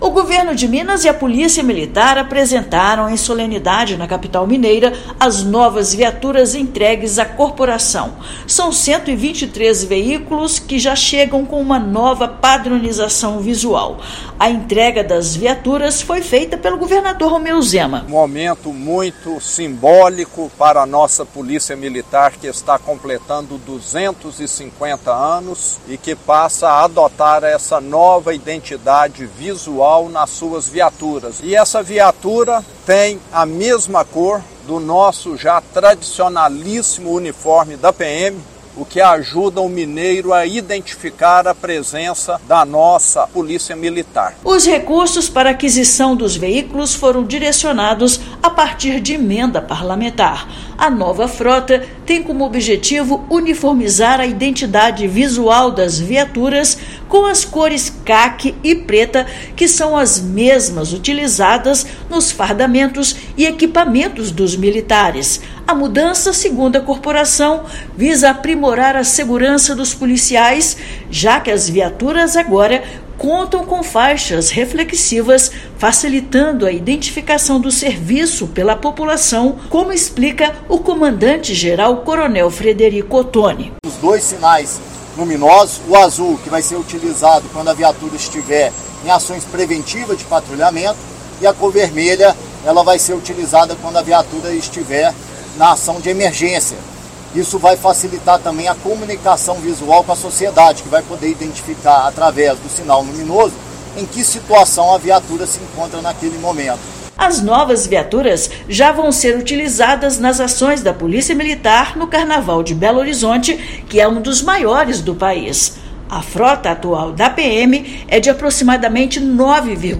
Veículos já serão utilizados no Carnaval 2025; ação é parte das celebrações pelos 250 anos de serviços prestados à população. Ouça matéria de rádio.